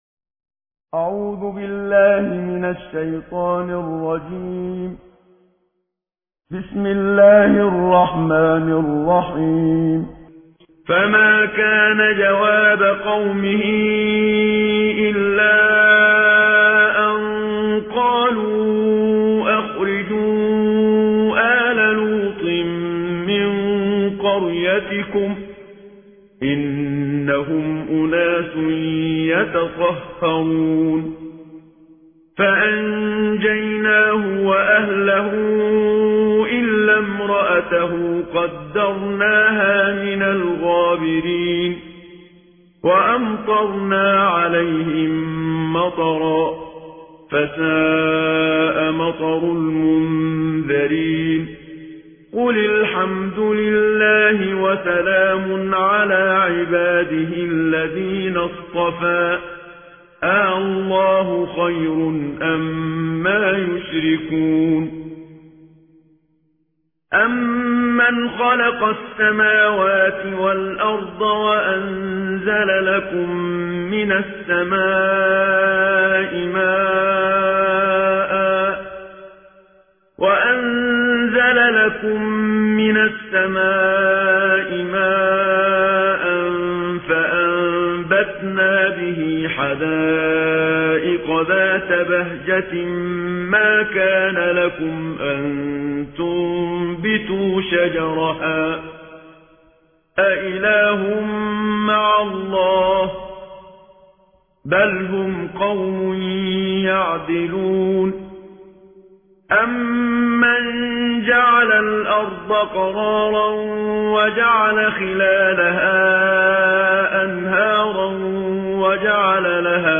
ترتیل جزء 20 قرآن کریم + دانلود